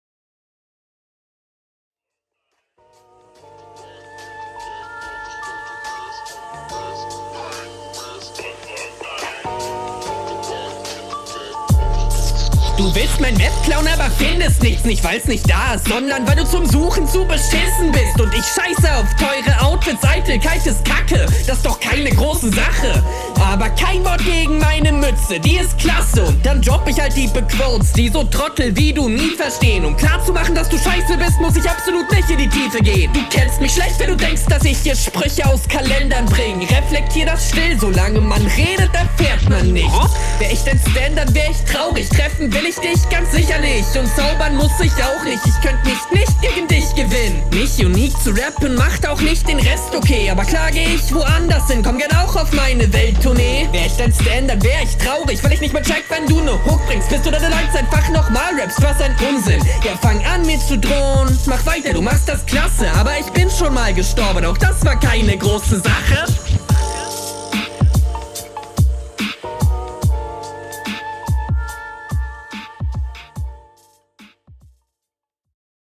Dein Flow gefällt mir gut und deine Art zu rappen ist …